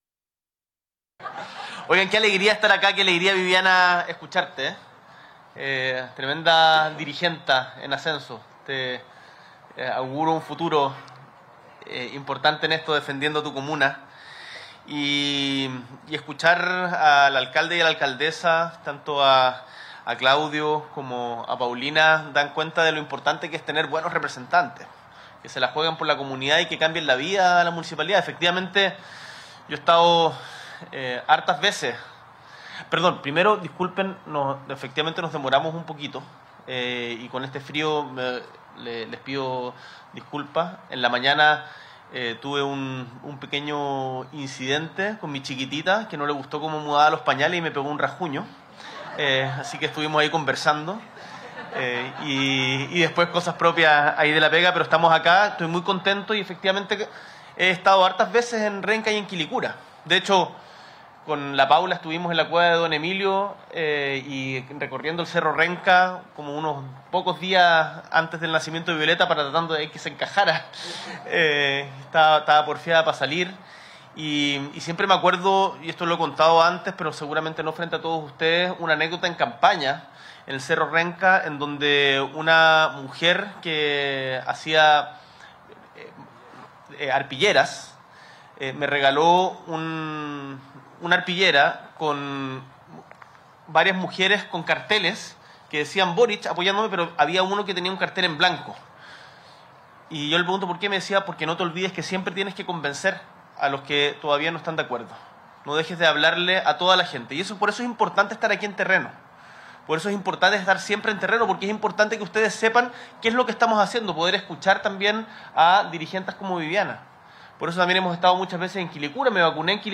S.E. el Presidente de la República, Gabriel Boric Font, encabeza la ceremonia de primera piedra del Túnel Lo Ruiz